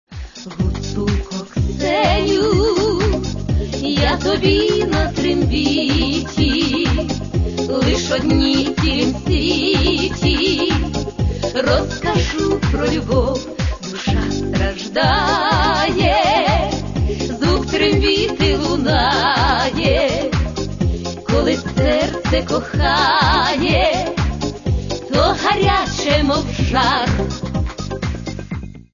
Каталог -> Эстрада -> Певицы